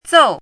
chinese-voice - 汉字语音库
zou4.mp3